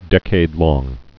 (dĕkād-lông, -lŏng, də-kād-)